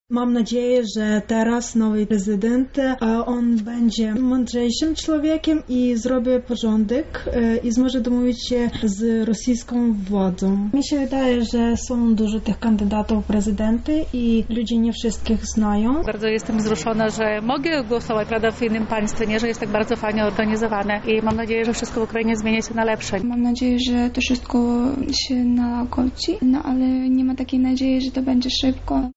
zapytała Ukraińców, co znaczy dla nich ta elekcja.